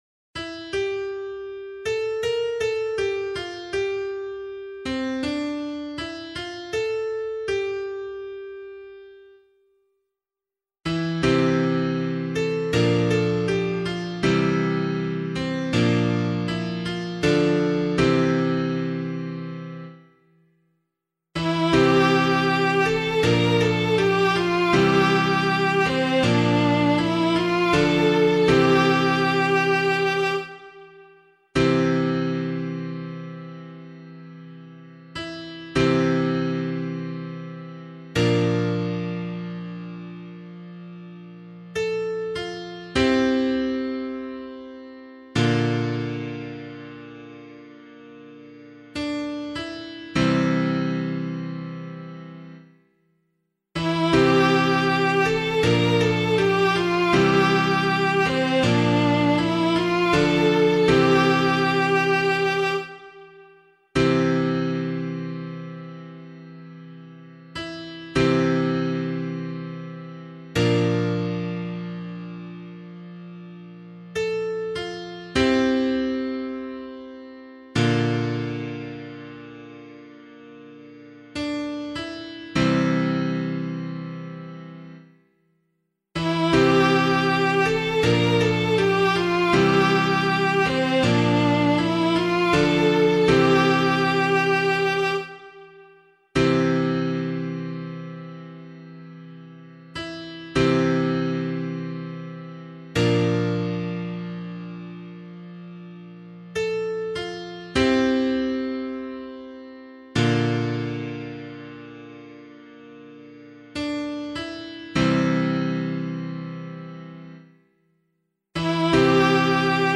036 Ordinary Time 2 Psalm C [APC - LiturgyShare + Meinrad 7] - piano.mp3